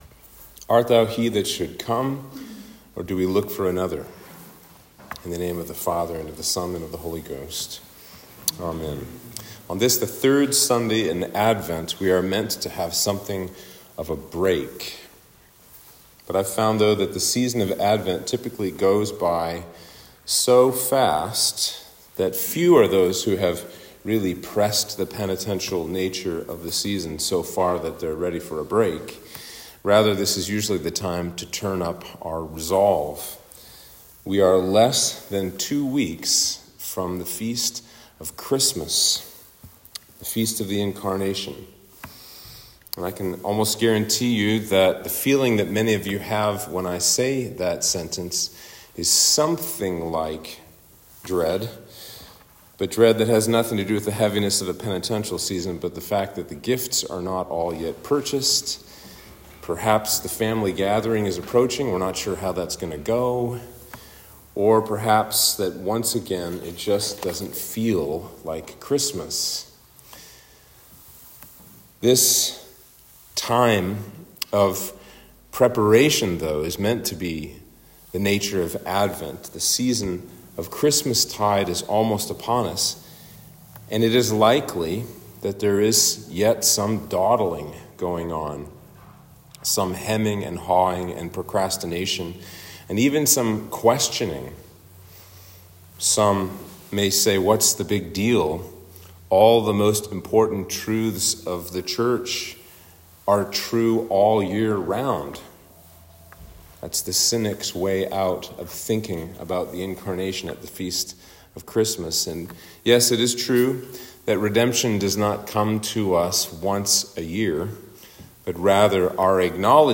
Sermon for Advent 3